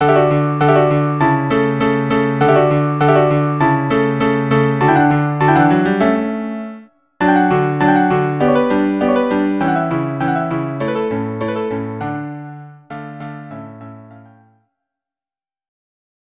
Instrumentation: Violin 1; Violin 2 or Viola; Cello